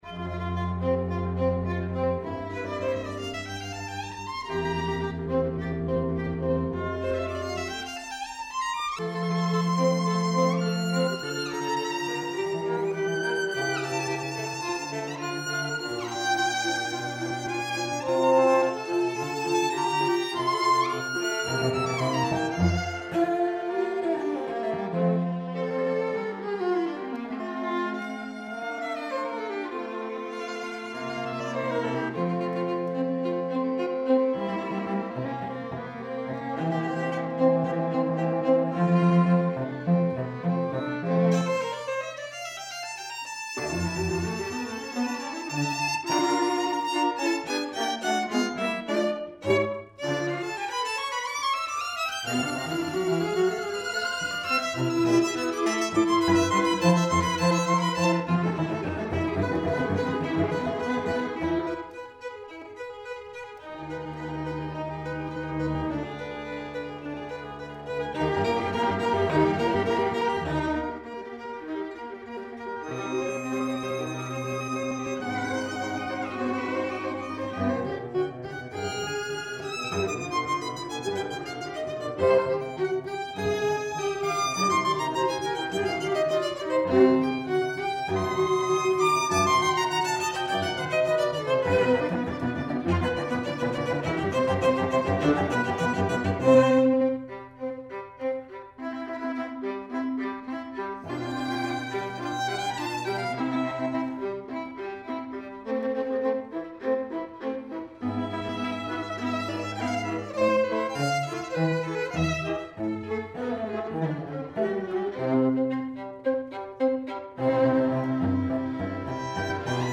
Soundbite 1st Movt